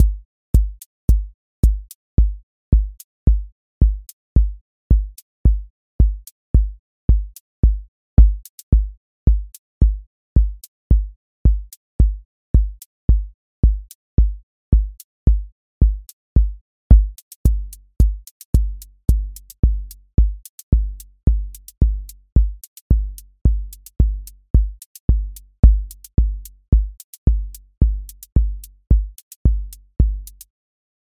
four on floor
steady house groove with lift return
• voice_kick_808
• voice_hat_rimshot
• voice_sub_pulse